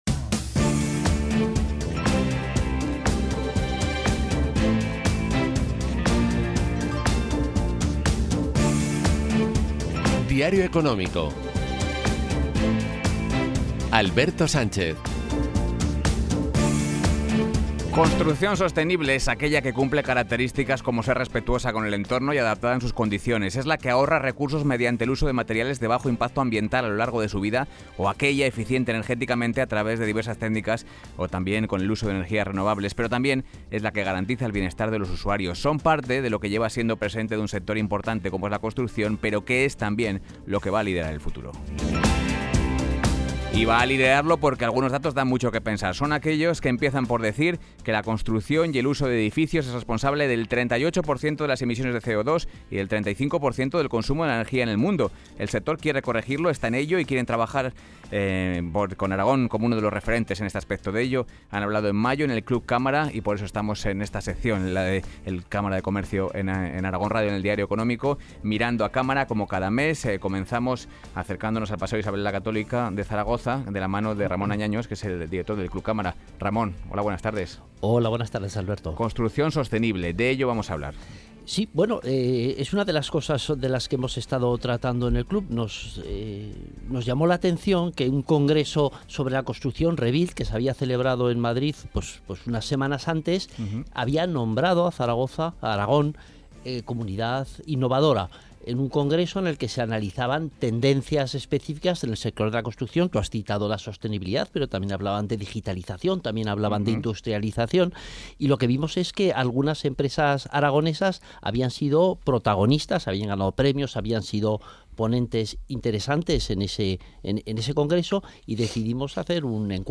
El COAATZ participó en esta tertulia en Aragón Radio, organizada por Cámara de Comercio.